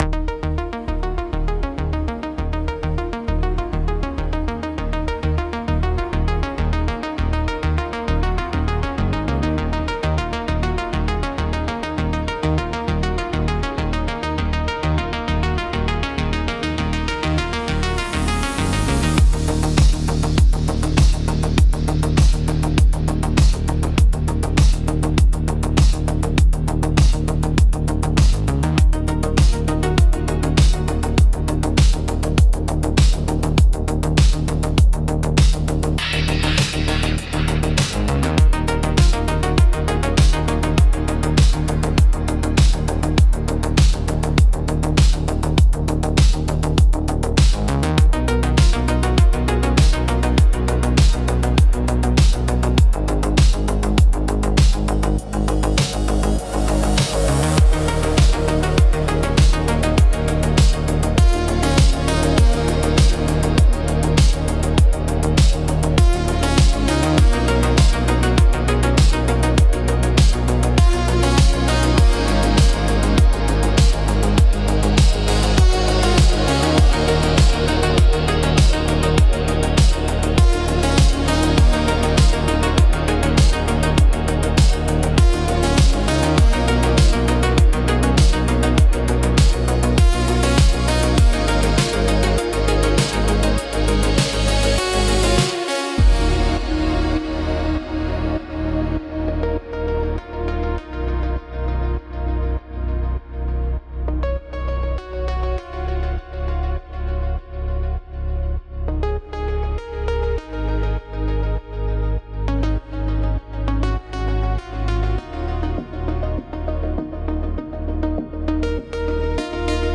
Escucha Música Muestra 2: instrumental etérea